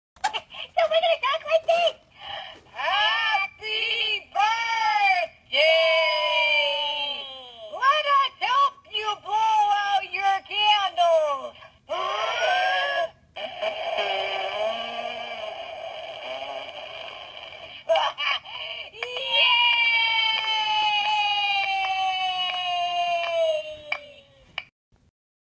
Happy Birthday In Slow Motion! is a hoops&yoyo greeting card with sound made for birthdays.
Card sound